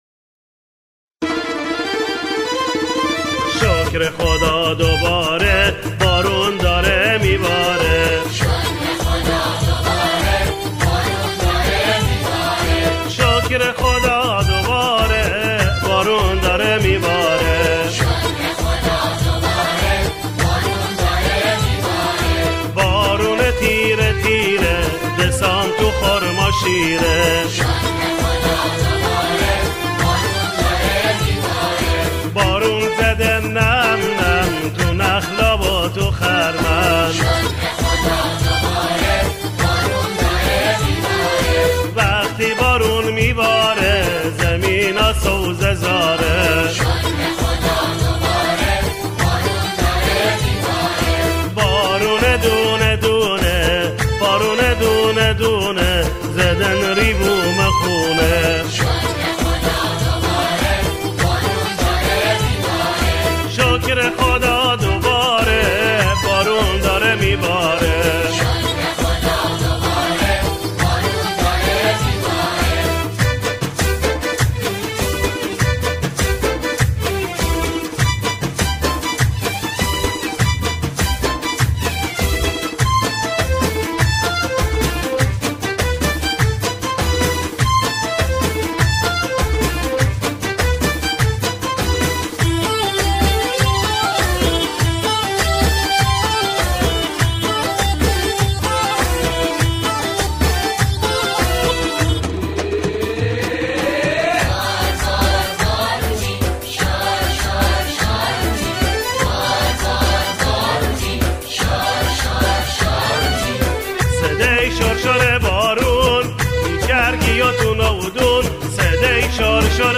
همخوانی
گروهی از جمعخوانان